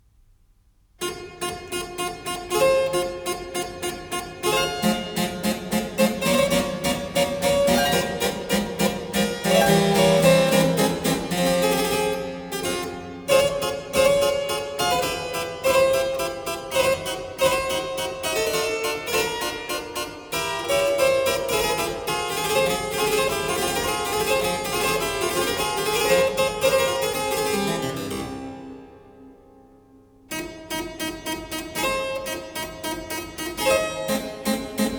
Classical
Жанр: Классика